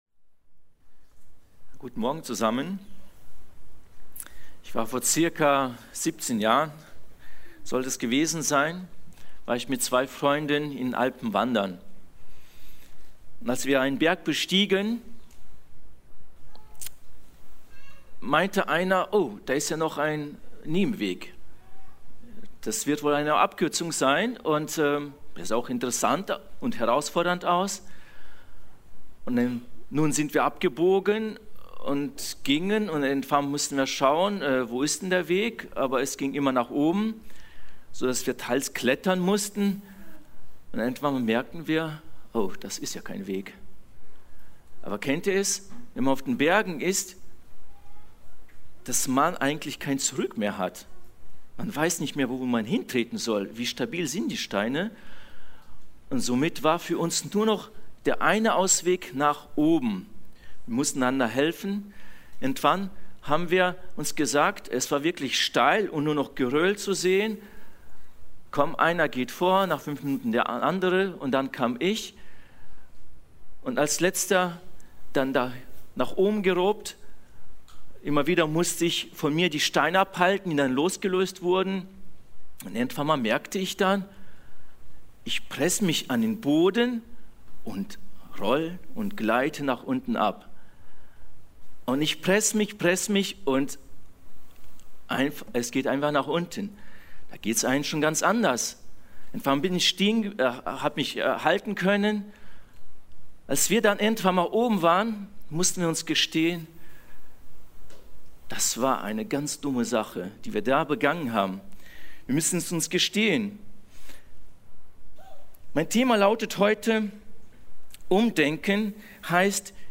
Predigten – Seite 6 – Bibelgemeinde Barntrup